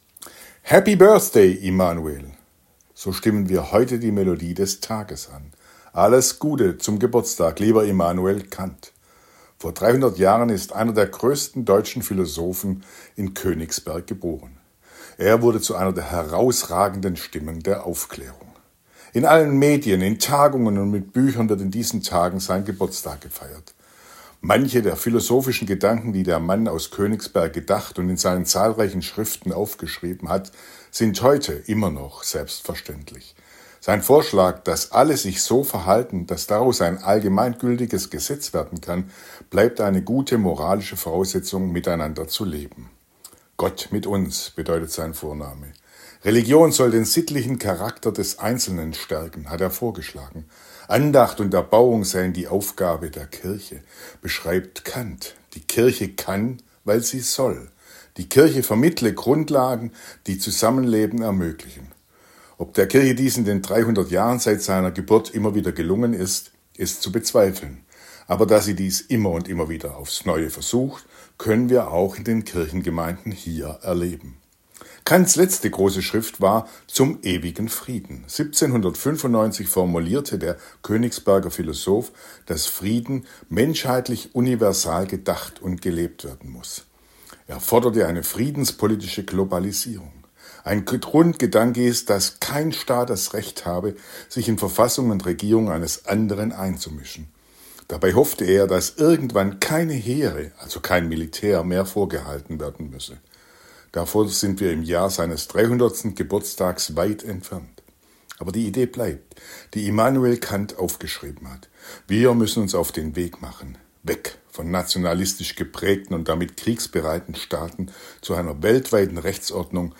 Radioandacht vom 22. April